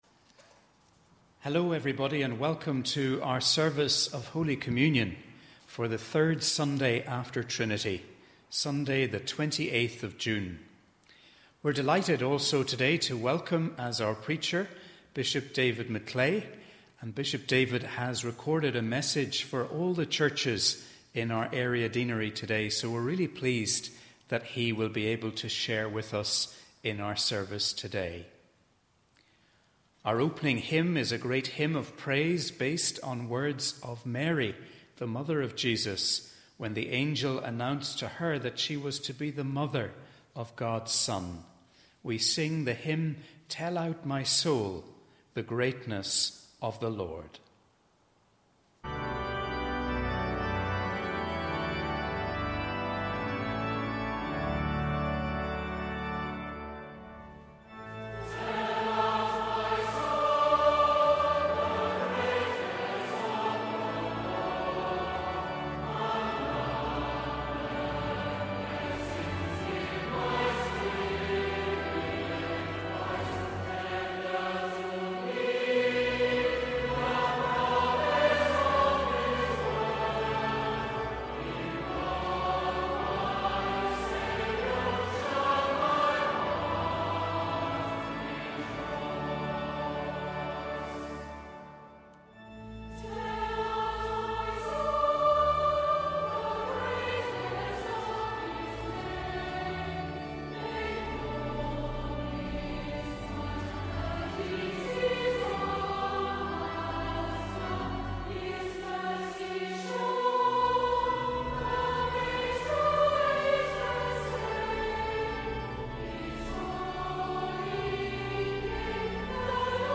We are delighted that you are able to join us for this service of Holy Communion. We welcome as our preacher today, Bishop David McClay, who has a message for all the churches in our Area Deanery.